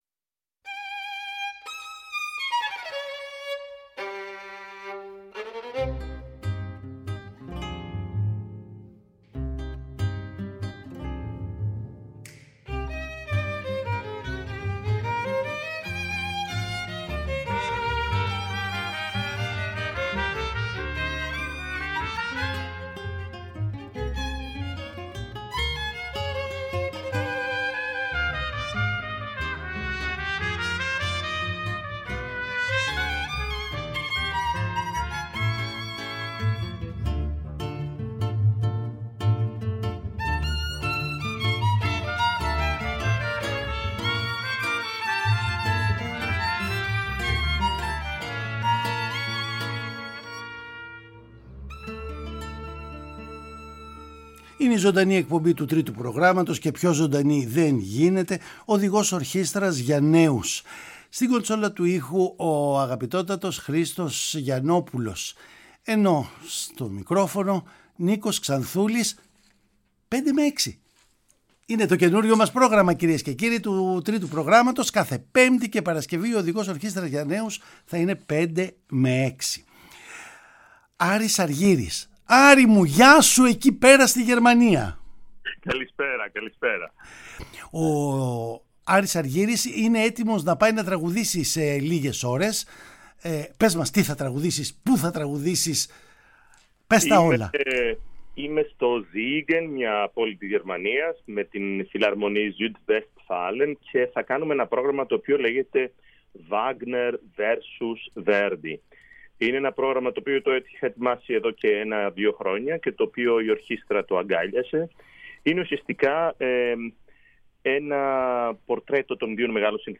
Ας τον ακούσουμε ζωντανά σε μια συνέντευξη εφ’ όλης της ύλης αλλά και σε έργα θαυμάσια ερμηνευμένα του ρεπερτορίου.
Παραγωγή-Παρουσίαση: Νίκος Ξανθούλης